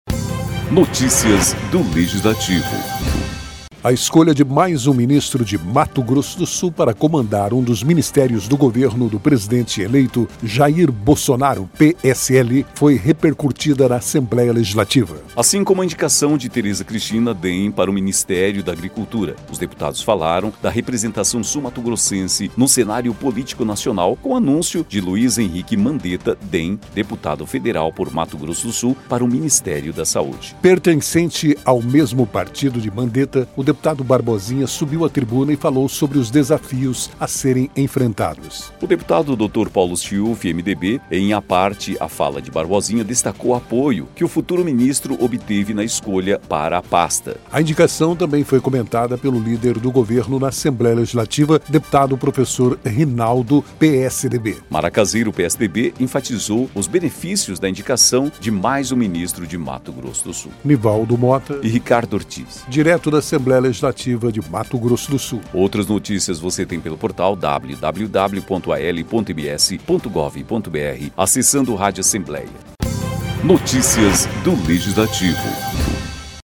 Pertencente ao mesmo partido de Mandetta, o deputado Barbosinha subiu à tribuna e falou sobre os desafios a serem enfrentados.